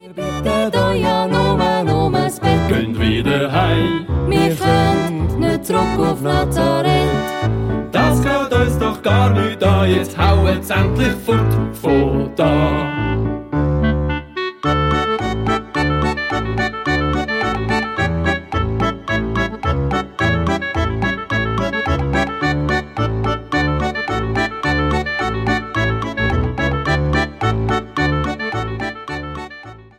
Alte Schweizer Weihnachtslieder sanft renoviert